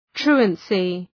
{‘tru:ənsı}
truancy.mp3